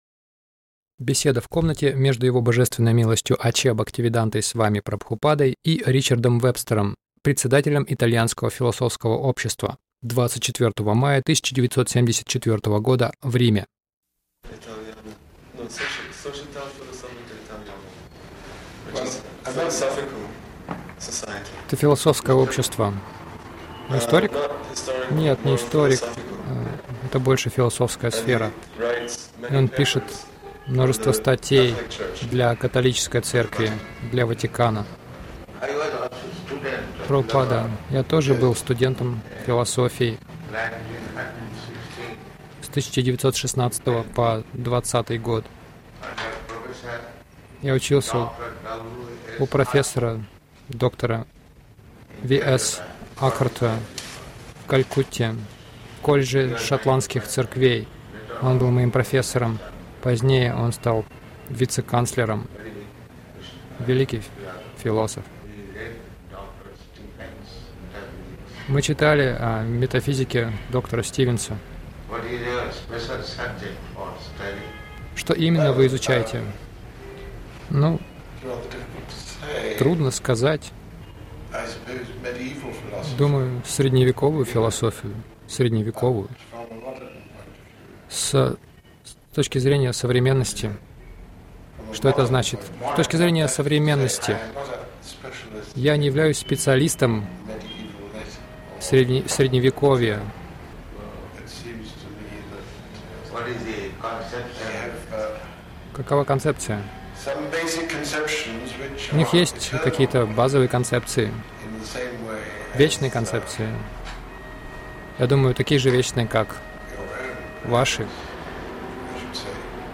Беседа в комнате — Проблема с политиками и лидерами